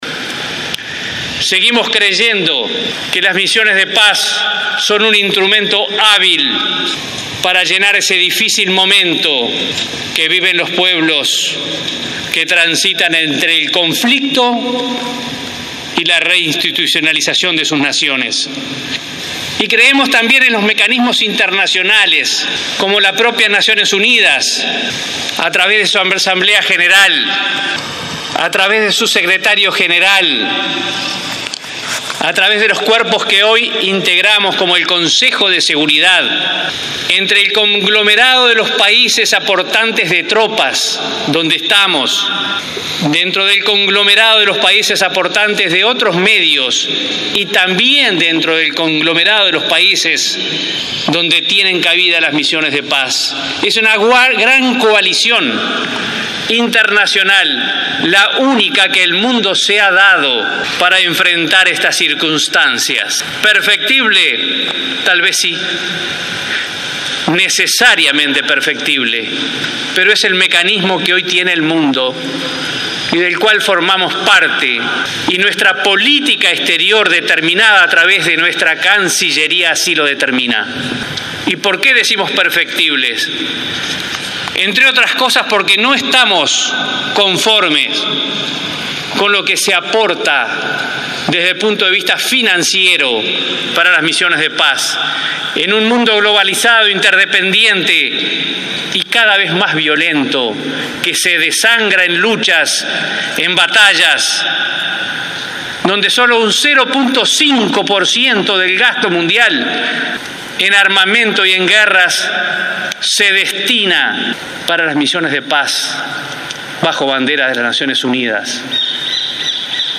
El ministro de Defensa Nacional, Jorge Menéndez, advirtió que “no está conforme” con lo que se aporta desde el punto de vista financiero para las misiones de paz y advirtió que solo se destina el 0,5 % del gasto mundial en armamento y guerras. El jerarca encabezó el acto homenaje a los 13 mil efectivos que estuvieron en Haití desde 2004 hasta el presente.